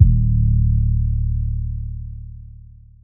god first 808.wav